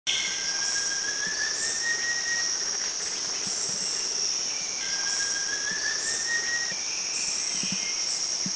Blue Fantail
Rhipidura superciliaris
BlueFantail.mp3